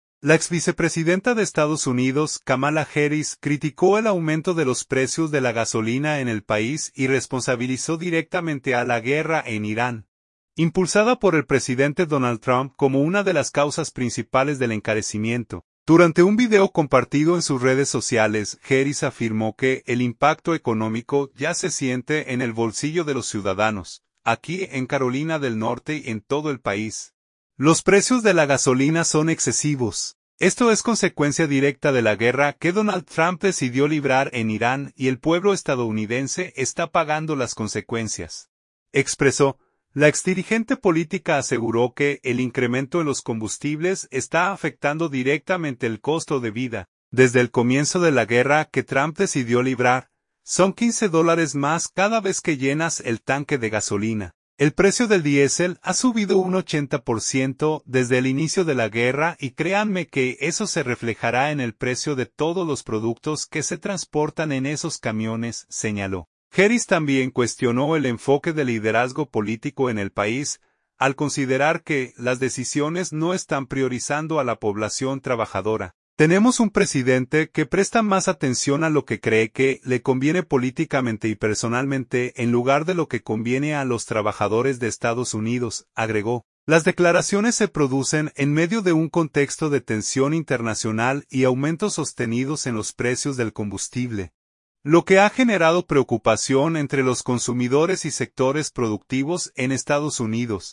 Durante un video compartido en sus redes sociales, Harris afirmó que el impacto económico ya se siente en el bolsillo de los ciudadanos.